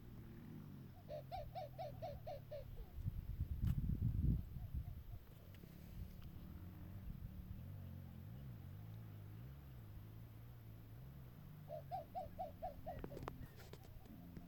Tapaculo (Scelorchilus albicollis)
Al menos tres vocalizando, uno observado de espectacular forma!
Nombre en inglés: White-throated Tapaculo
Localidad o área protegida: Localidad Cuesta Ibacache - Oriente
Condición: Silvestre
Certeza: Observada, Vocalización Grabada